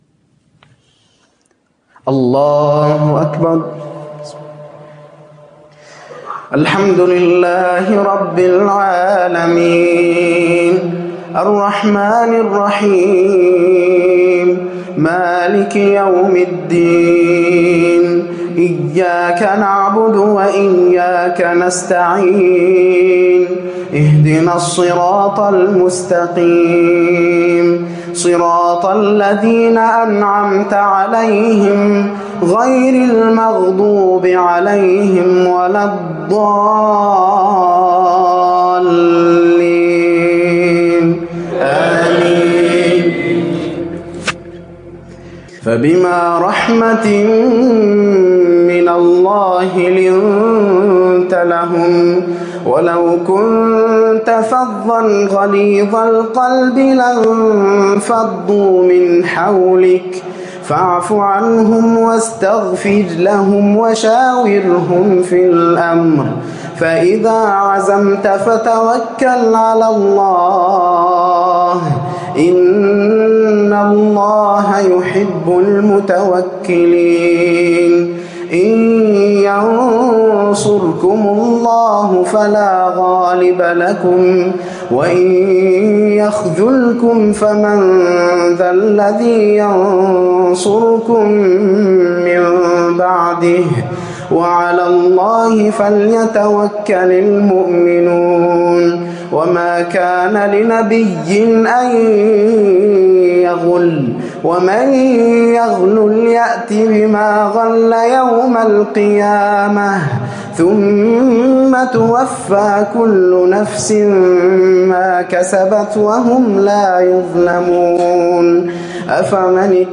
قرأ من سورة آل عمران من الآية 159 إلى 173